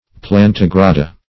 Search Result for " plantigrada" : The Collaborative International Dictionary of English v.0.48: Plantigrada \Plan`ti*gra"da\, n. pl.